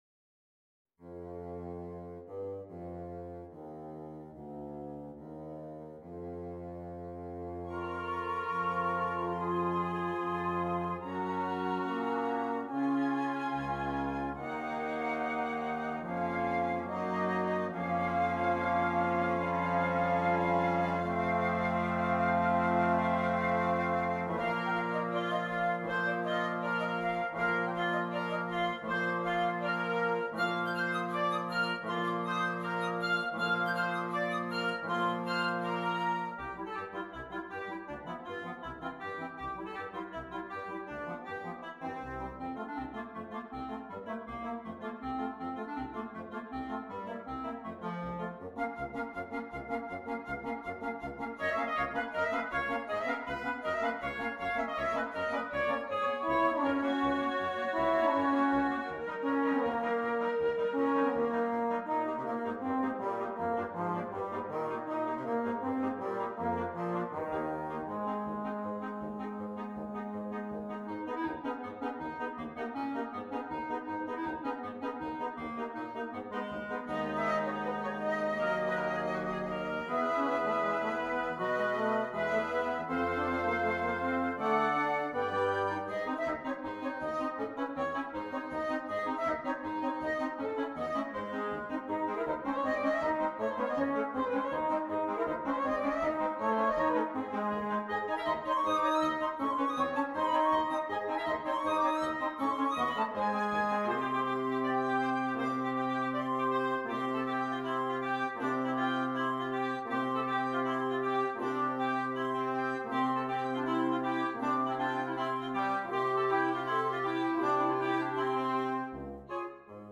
Woodwind Quintet
Fun and audience friendly.